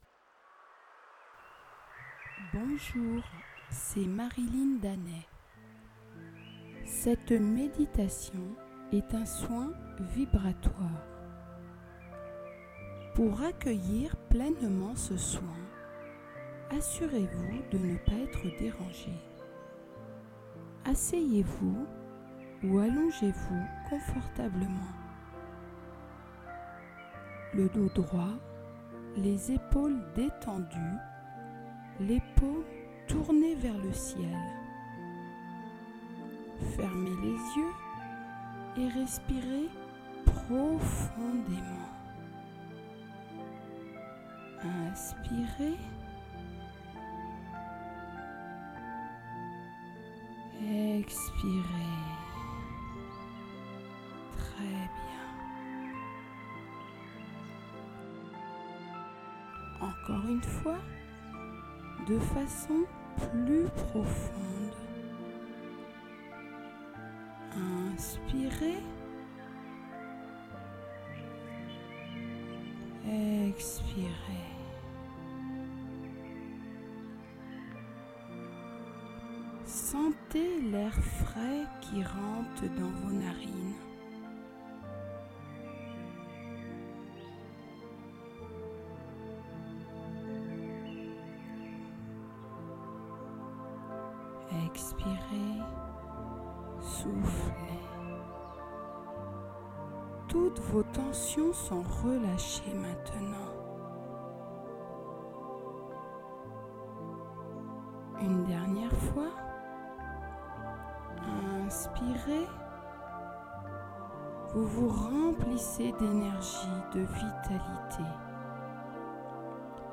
Laissez vous bercer par ma voix pour relâcher toutes vos tensions, vous détendre et être à l'écoute de votre corps tout en recevant un soin vibratoire